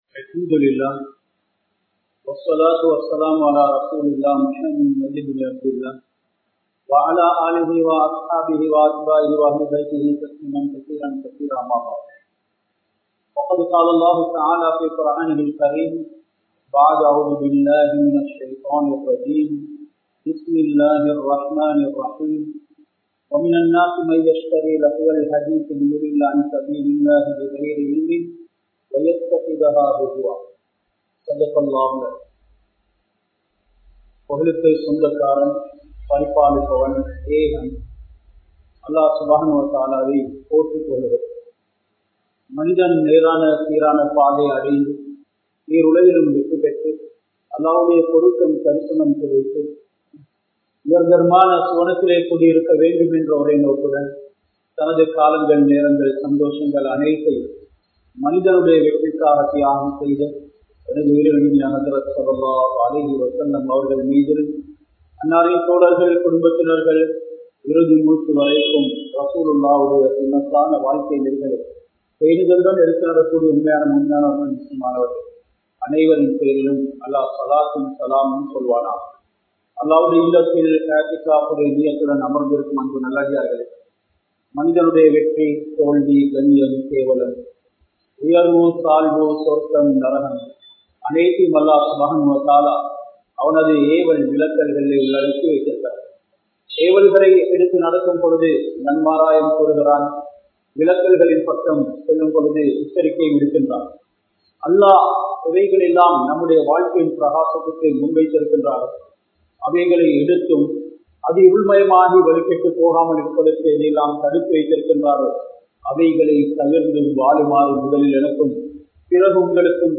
Haraamaana Paarvaihalum Indraya Samoohamum (ஹராமான பார்வைகளும் இன்றைய சமூகமும்) | Audio Bayans | All Ceylon Muslim Youth Community | Addalaichenai
Muhiyadeen Jumua Masjith